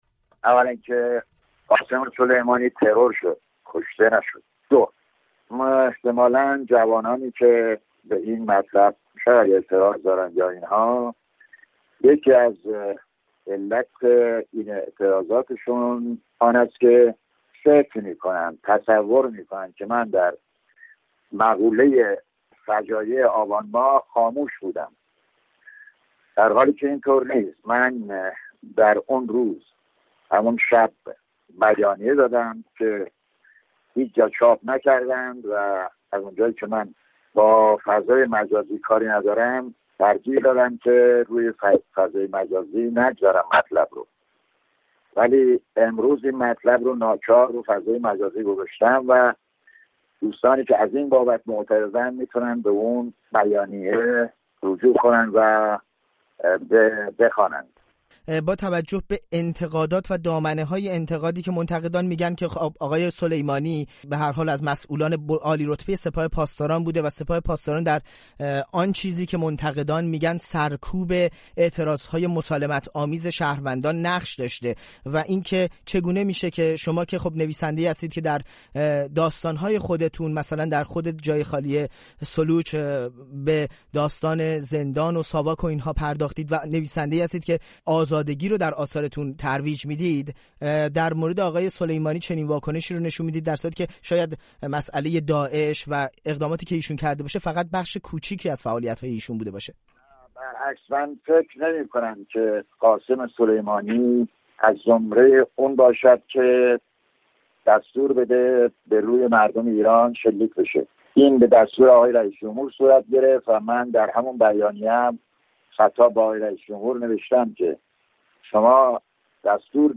گفت‌وگو با محمود دولت‌آبادی، نویسنده